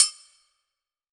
WAGOGO HI.wav